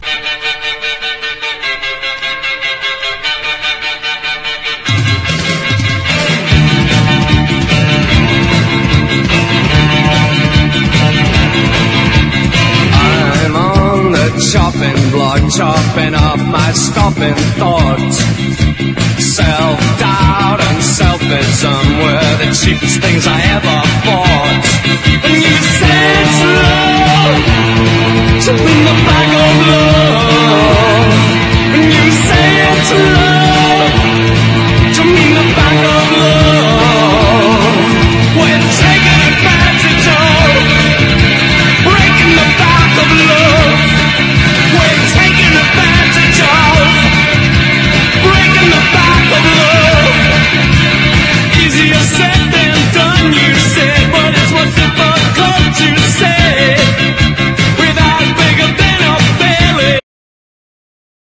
ROCK / 80'S/NEW WAVE. / 80'S / NEW WAVE / NEO PSYCHE
きらびやかなホーンセクションが入ってノーザン・ソウルのような多幸感に満ち溢れた
ぎらついいたシンセ・ポップ
リズムボックス＆ベース・シーケンスをバックにDEE-JAYが盛り上げる